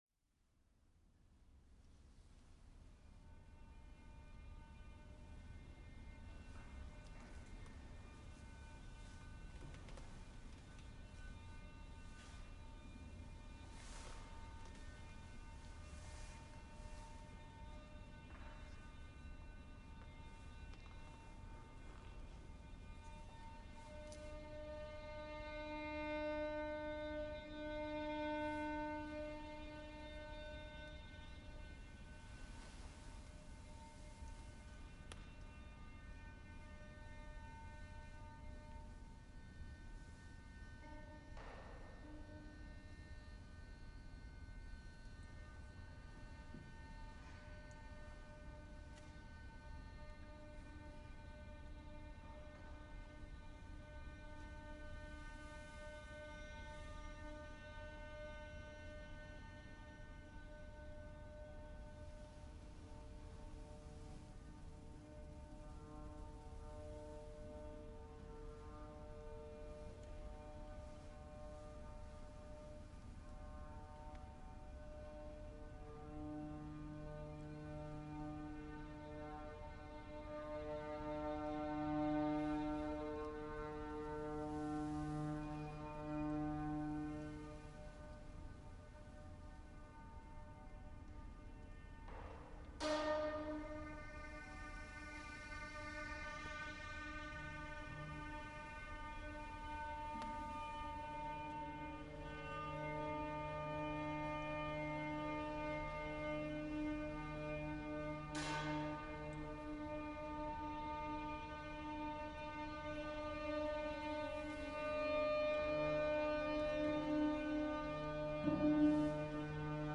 cdnnns-aioo (2011) canon in dos for string orchestra
fw12-orchesterprojekt-ernen-canon-in-dos.mp3